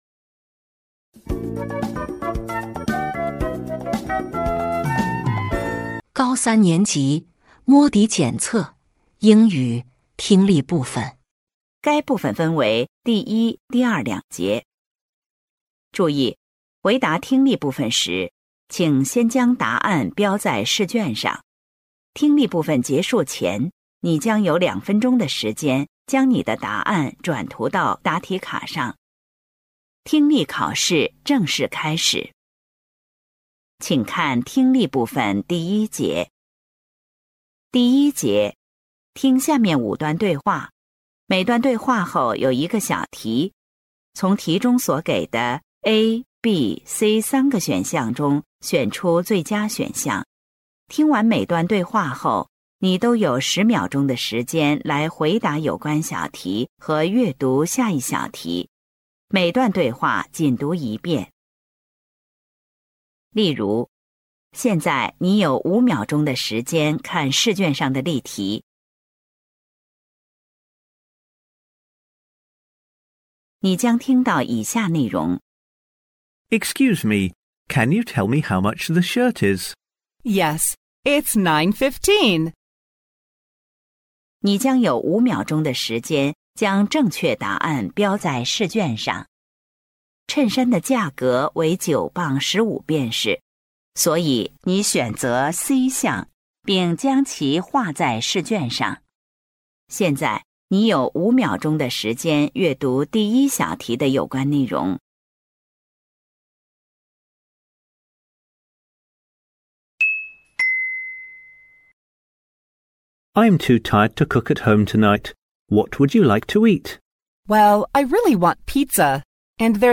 成都市蓉城名校联盟2026届高三上学期开学联考英语听力.mp3